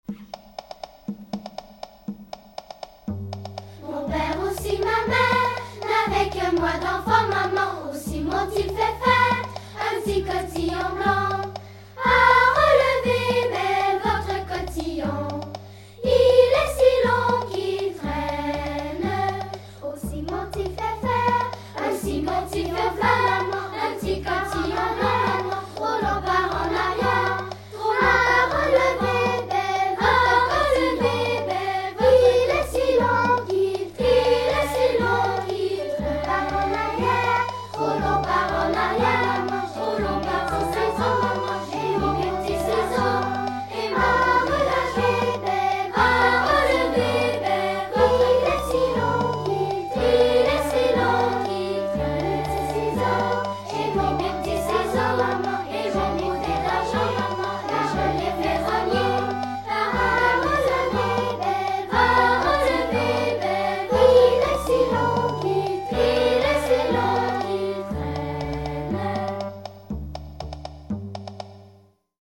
Musique vocale